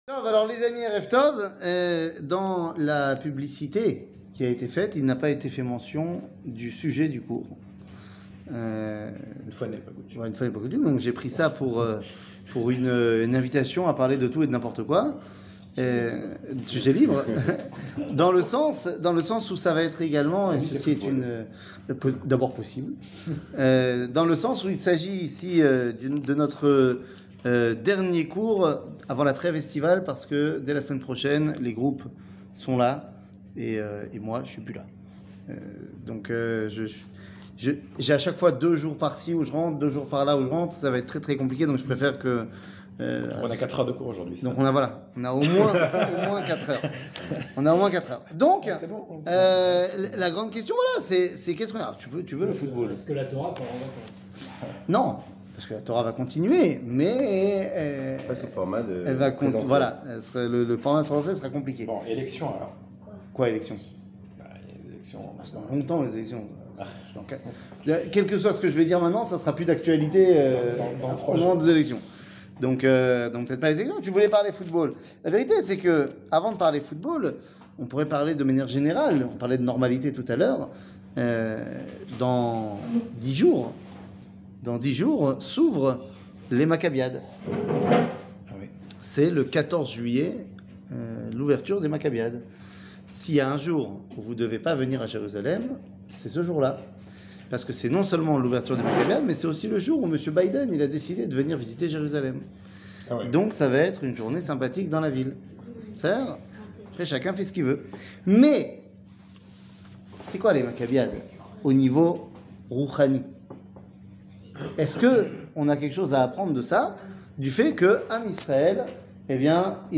שיעור מ 06 יולי 2022 54MIN הורדה בקובץ אודיו MP3